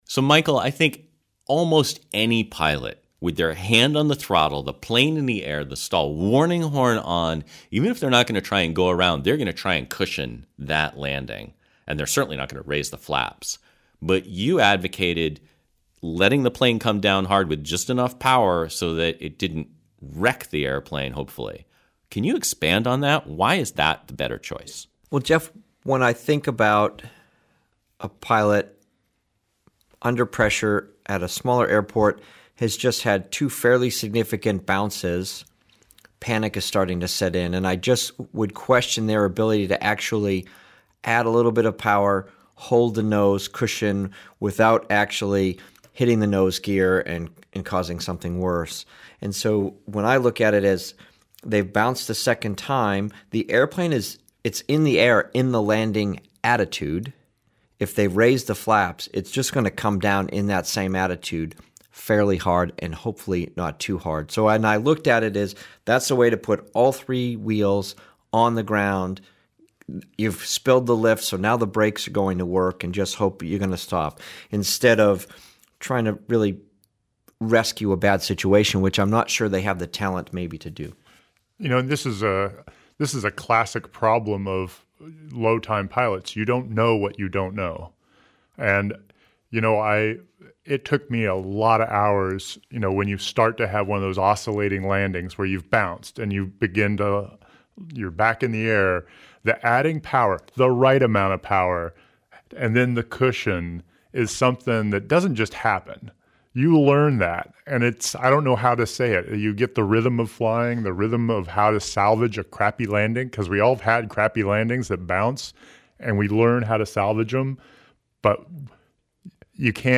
The_Impossible_go-Around_roundtable.mp3